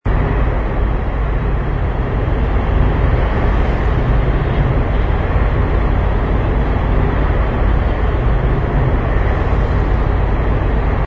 ambience_cityscape_cave.wav